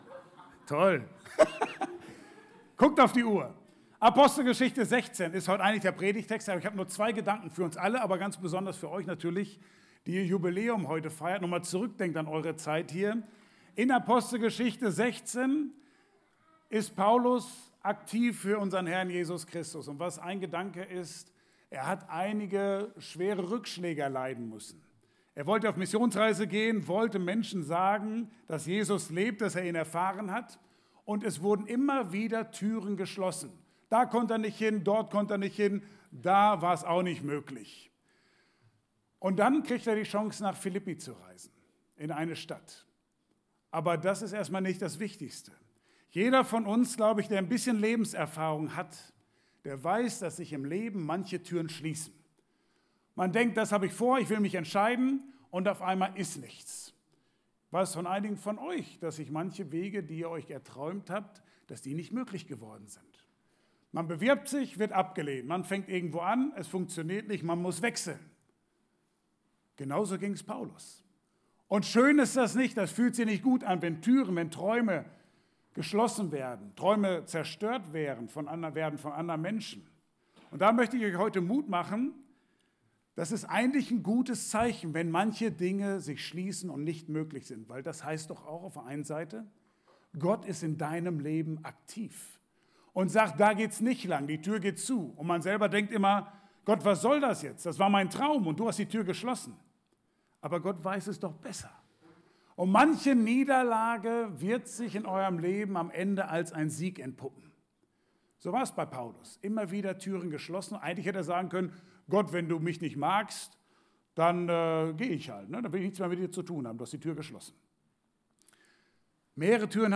Passage: Apostelgeschichte 16, 23-34 Dienstart: Gottesdienst « Nachricht für dich von der Weisheit Markus 4